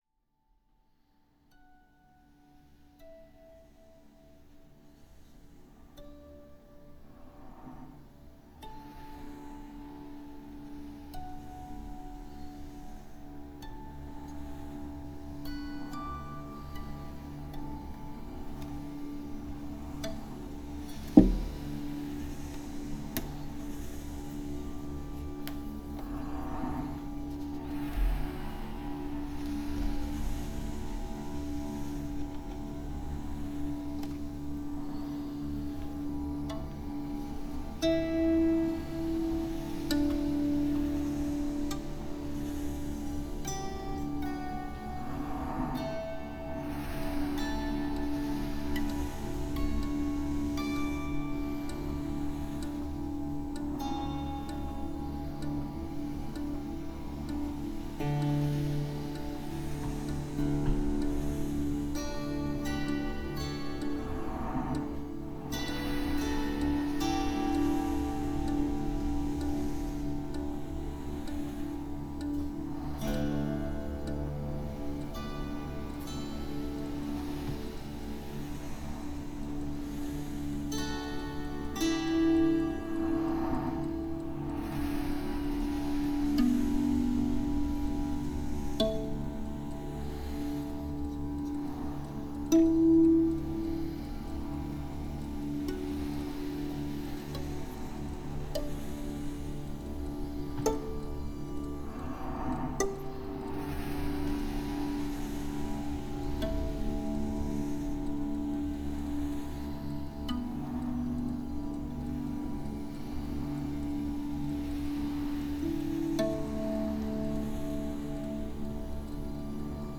Experimental, Electronic, Classical, Folk
elektrooniline kandleduo